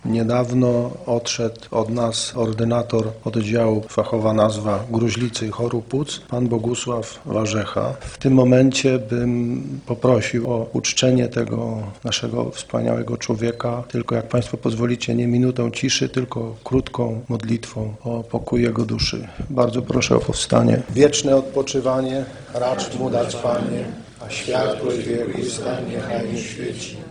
'Wieczne odpoczywanie’ radni odmówili z inicjatywy radnego powiatowego PiS Marka Kamińskiego: